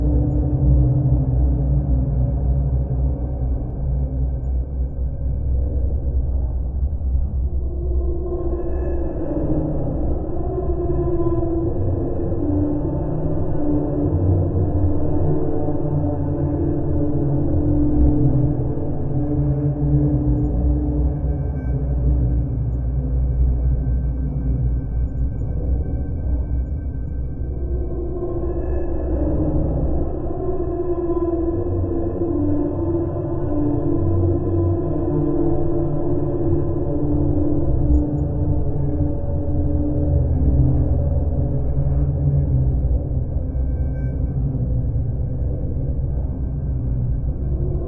描述：一种诡异的无人机声音。让孩子们夜不能寐。
Tag: 环境 爬行 无人驾驶飞机